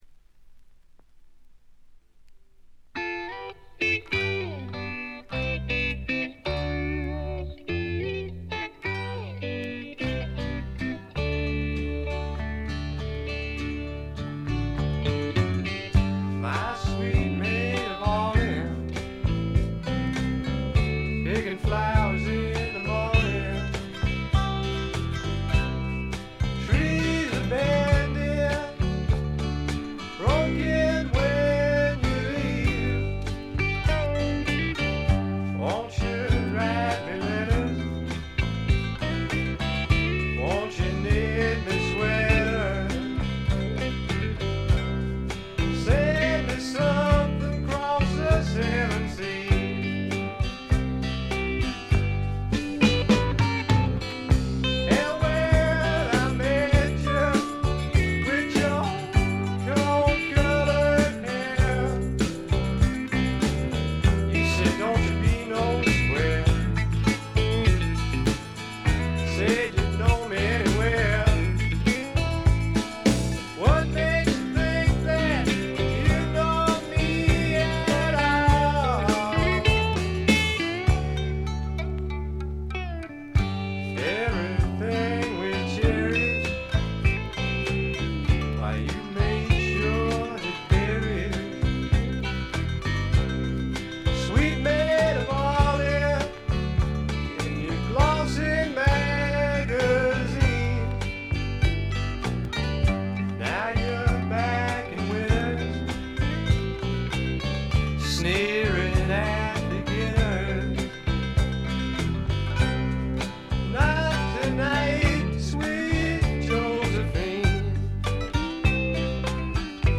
ジャケットはまるで激渋の英国フォークみたいですが、中身はパブロック／英国スワンプの裏名盤であります。
カントリー風味、オールド・ロックンロールを元にスワンプというには軽い、まさに小粋なパブロックを展開しています。
試聴曲は現品からの取り込み音源です。